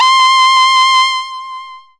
描述：本样本是"基本锯齿波4"样本包的一部分。它是一个多重样本，可以导入到你最喜欢的采样器中。它是一个基本的锯齿波形，在声音上有一些低通滤波。也有一些超载的声音，这使得某些频率产生了一些共振。最高的音高显示出一些奇怪的别离音高弯曲的效果。在样本包里有16个样本，均匀地分布在5个八度（C1到C6）。样本名称中的音符（C、E或G）确实表明了声音的音高。这个声音是用Reaktor的用户库中的Theremin模拟合奏制作的。之后，在Cubase SX中应用了归一化和淡化。
标签： 基本波形 多样本 Reaktor的 只见
声道立体声